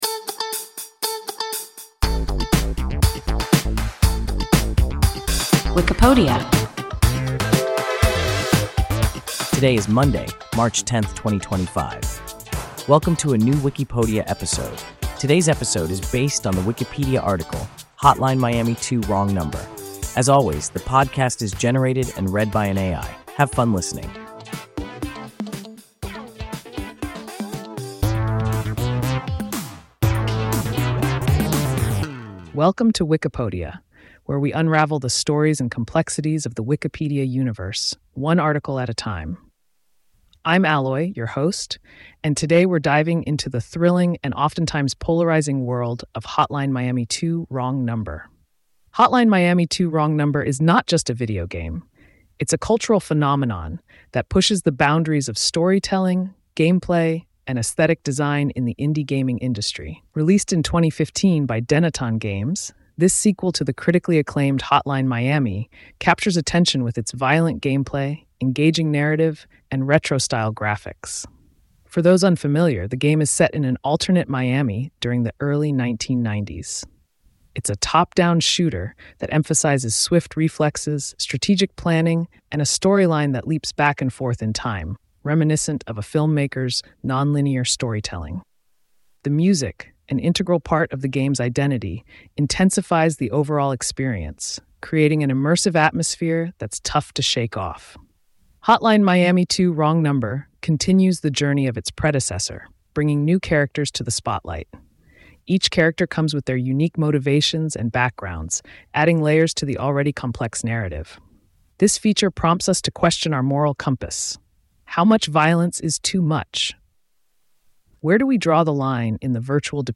Hotline Miami 2: Wrong Number – WIKIPODIA – ein KI Podcast